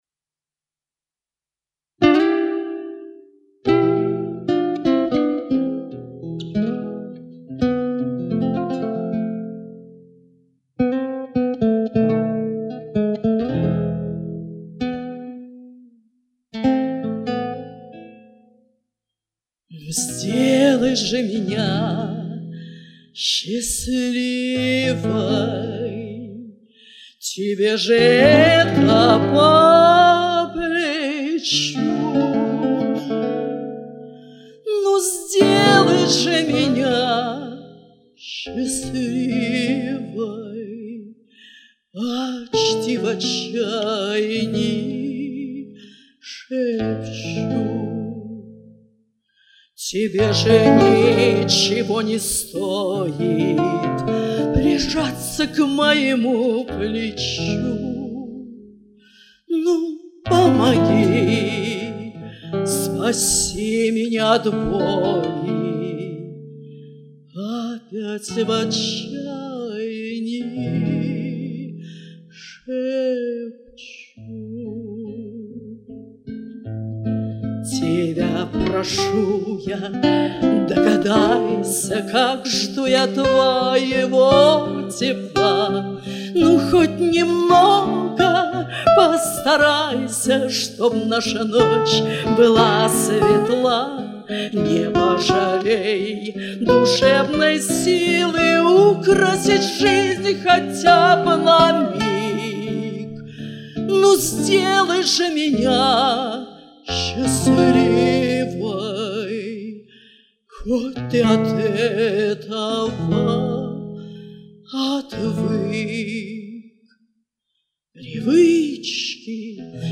Значит поём,может быть кто -то услышит как поют две женщины.